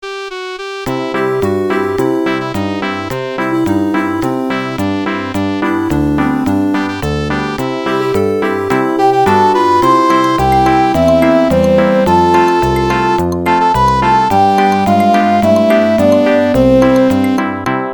以前SH-51で作成したmmfファイルをmp3ファイルに録音し直しました。
再生する機種により、音は、多少異なって聞こえます。